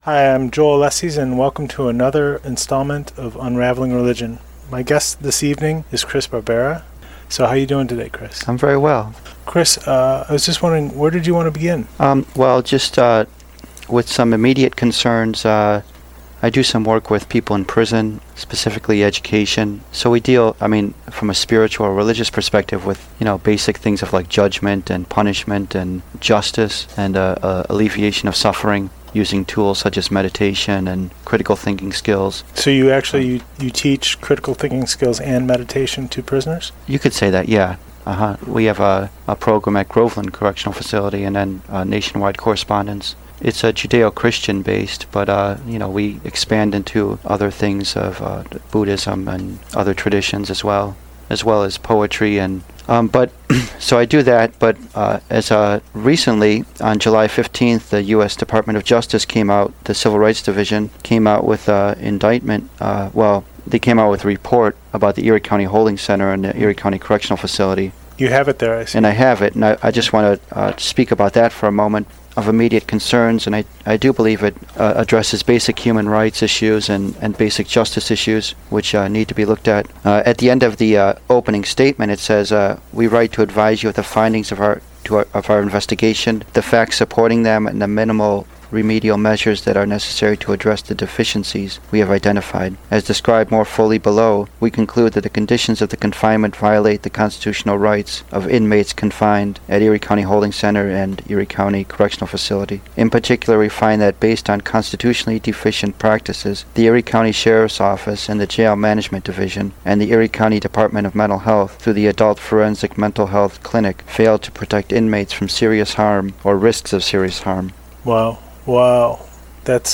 'Real Flowers Of This Painful World,' Compassion Manifest For All Beings, Spirituality Forged Through Practical Application: A Conversation